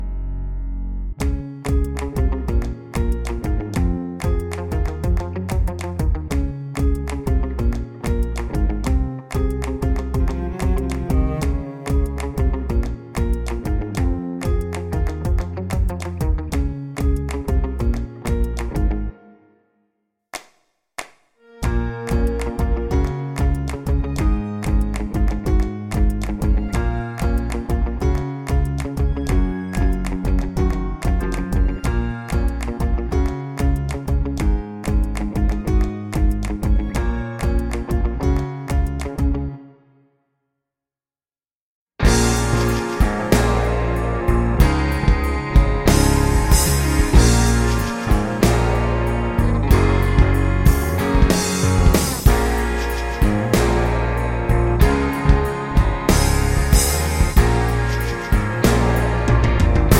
no Backing Vocals Musicals 3:15 Buy £1.50